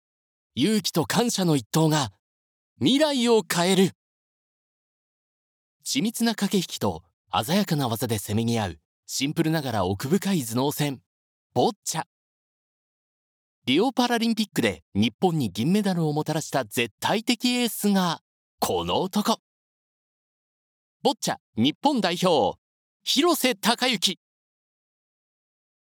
Vielseitige, zuverlässige, warme und beruhigende Stimme, die sich für Geschäfts-, Werbe- und Dokumentarfilme eignet.
Sprechprobe: Sonstiges (Muttersprache):
Japanese voice over artist. Versatile, reliable, warm and soothing voice suitable for business, commercials and documentaries.